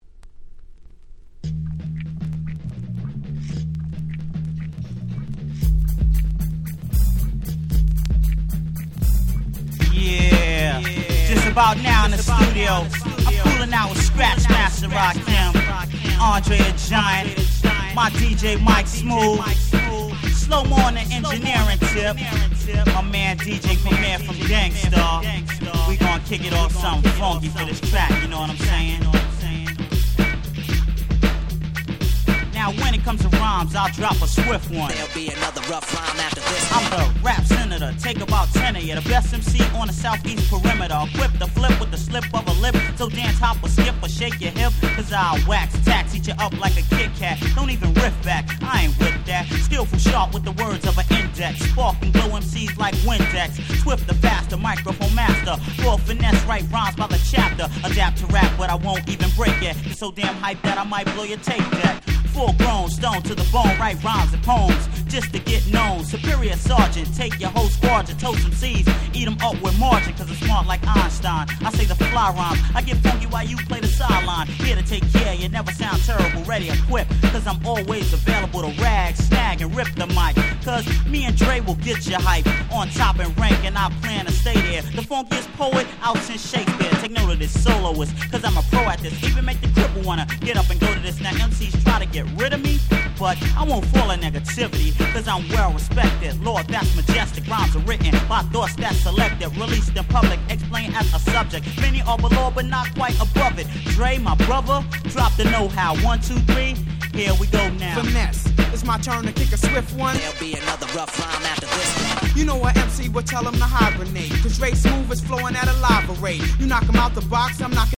90' Super Hip Hop Classics !!